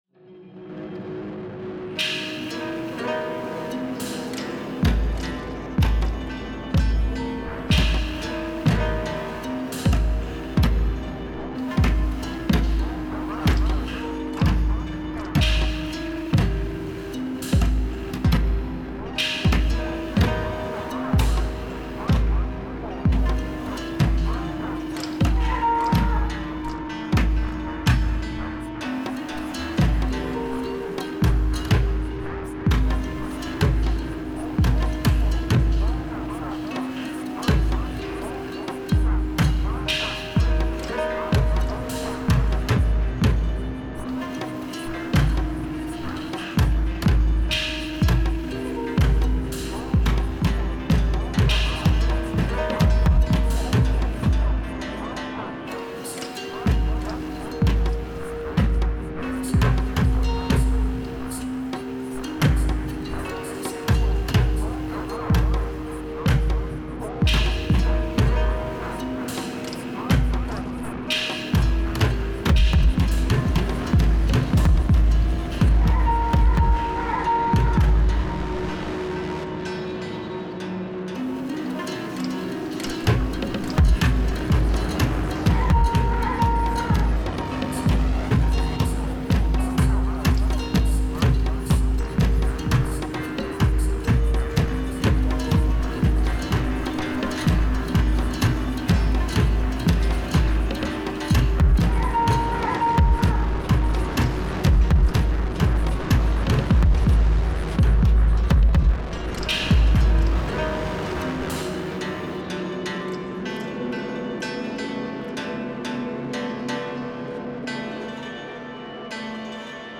Laissez vous emporter par ces sonorités étranges et planantes !
J’ai décidé d’utiliser mes lacunes à mon avantage en créant volontairement un morceau dont la structure ne soit pas répétitive, tout en ayant des patterns par instruments.
J’y ai ajouté un kit de drums, 2 synthés et 1 instrument à corde numériques ainsi qu’un synthé granulaire à partir de mon Ukulélé. Je me suis également occupée du mixage comprenant notamment de la distorsion et de la reverb.
Son : composition musicale, MAO, Ukulélé, sound design, prise de son avec un micro Lewitt 240 pro, montage et mixage sous Reaper. Album : Commencement, Piste 1. 2min09, Stéréo, 120bpm, format MP3, 320 kb/s, 44.100 Khz.